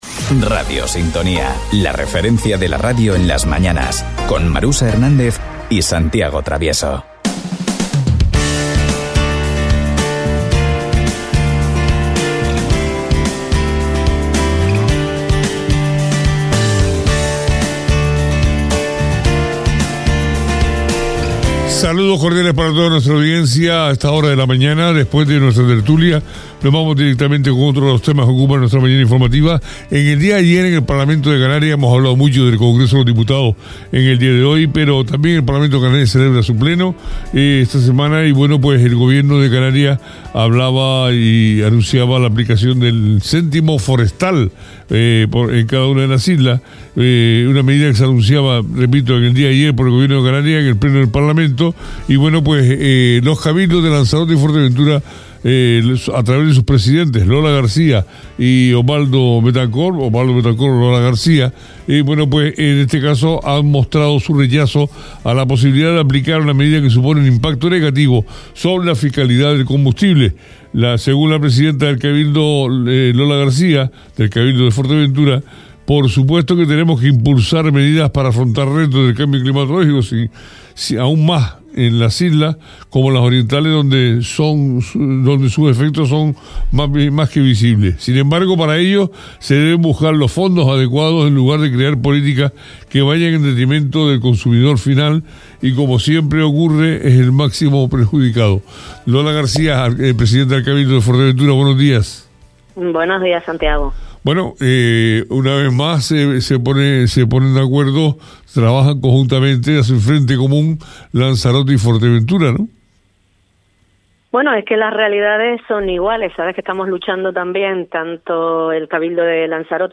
Lola García, presidenta del Cabildo nos habla sobre la negativa del Cabildo majorero, junto al de Lanzarote, a cobrar el céntimo forestal.
Entrevistas